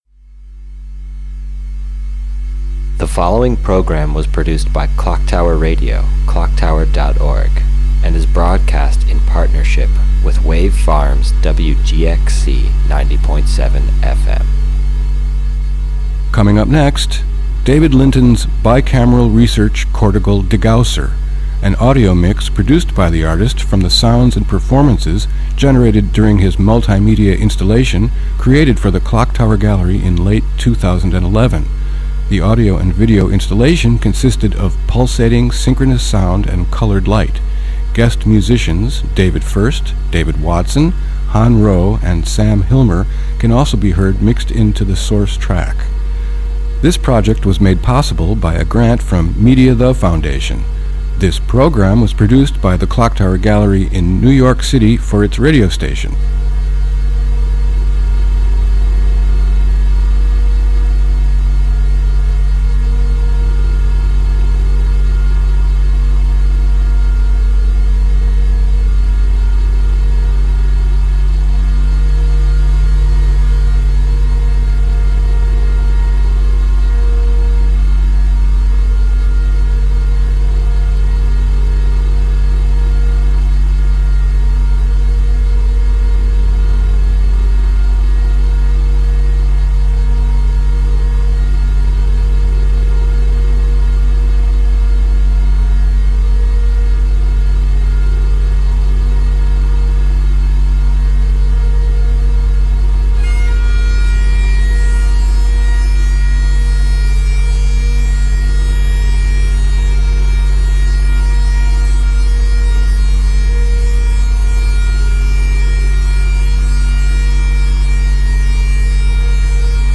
guitar
bagpipes
violin
saxophone